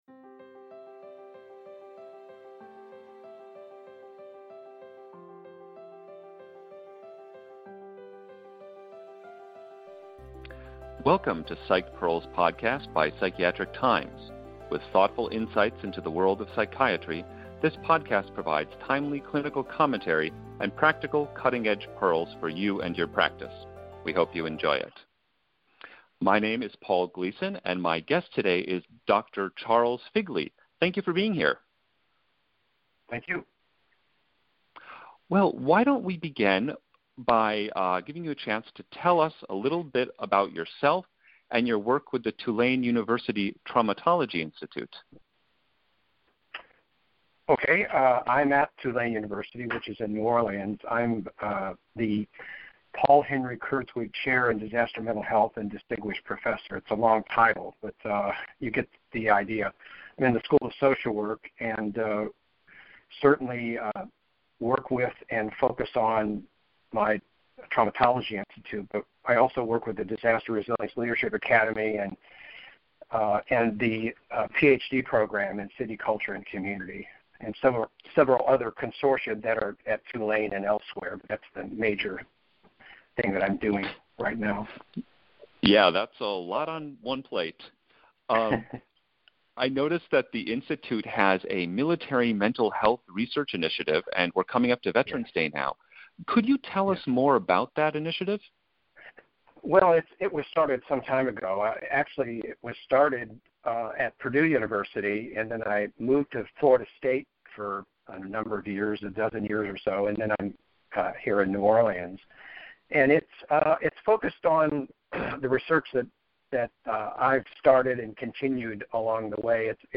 Psychiatric Times interviews